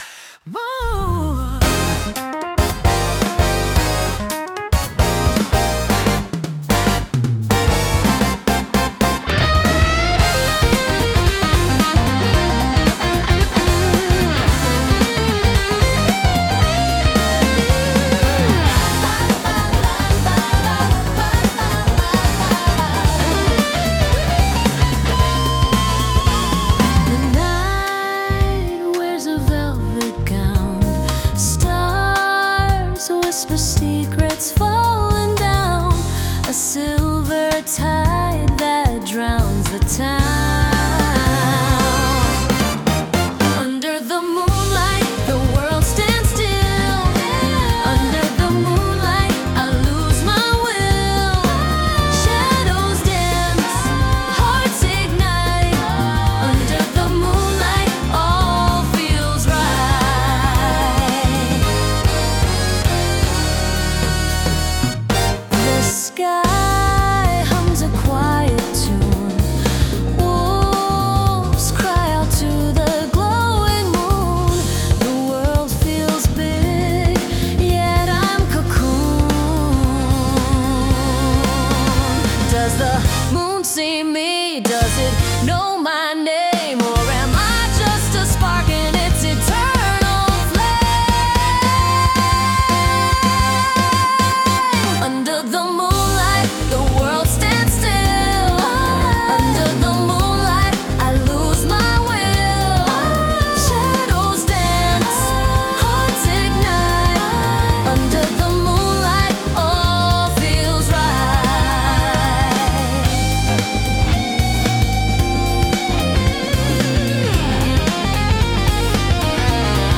アニメ音楽は、日本のアニメ主題歌をイメージしたジャンルで、ポップでキャッチーなメロディとドラマチックな展開が特徴です。